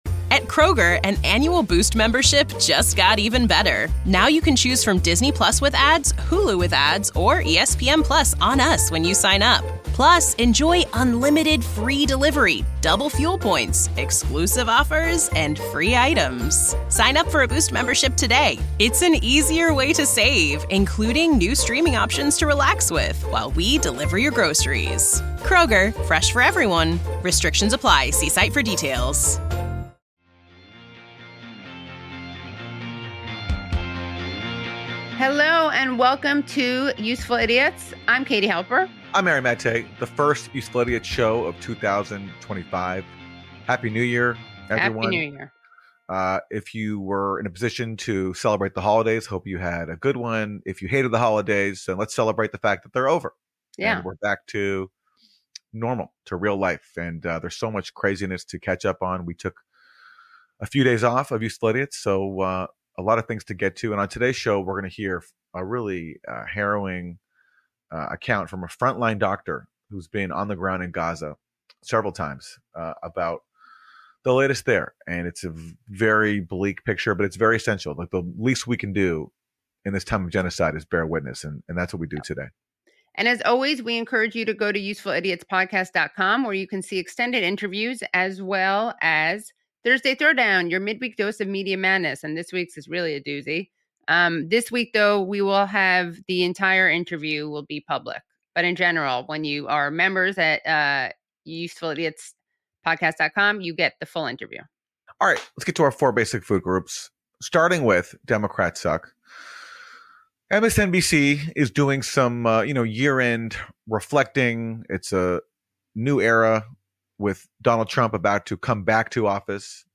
Useful Idiots is an informative and irreverent politics podcast with journalist Aaron Maté and podcaster/writer Katie Halper. Episodes feature analysis of the political news of the week and exclusive interviews, with humor, commentary and dissection of why both Republicans and Democrats suck.